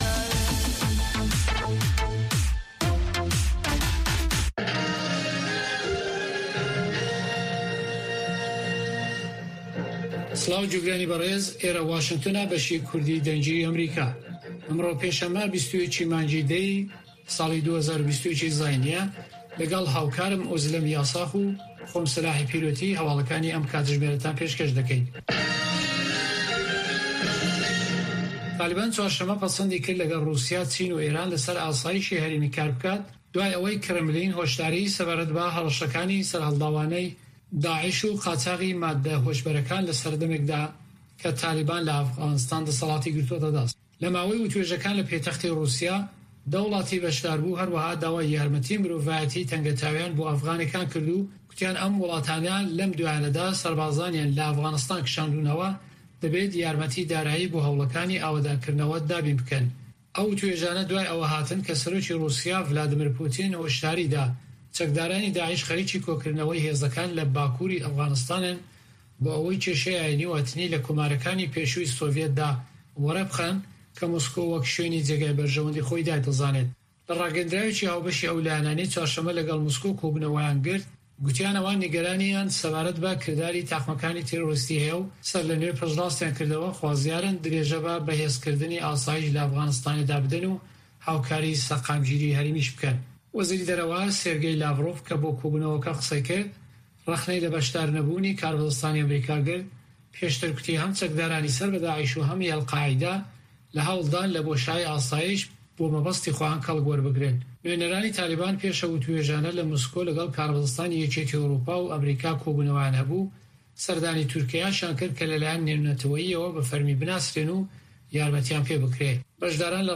هەواڵەکانی 1 ی شەو